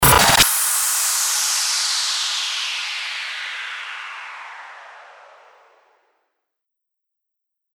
Genre: Blues.
FX-1459-STARTER-WHOOSH
FX-1459-STARTER-WHOOSH.mp3